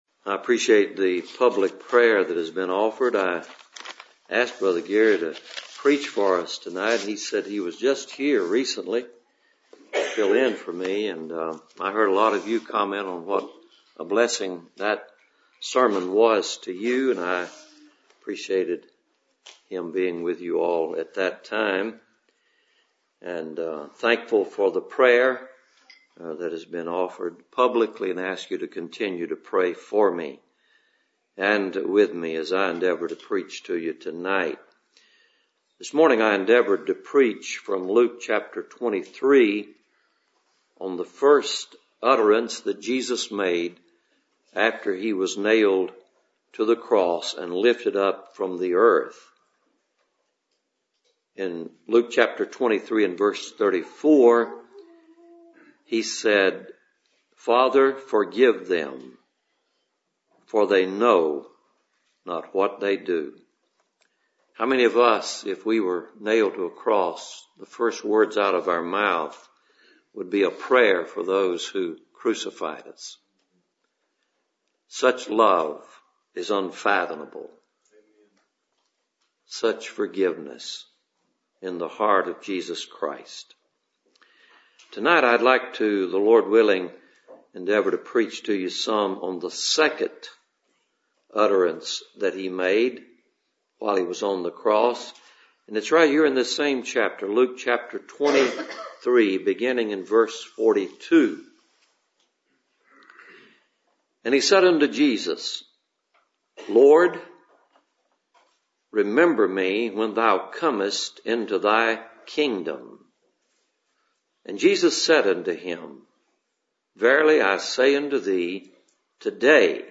Service Type: Cool Springs PBC Sunday Evening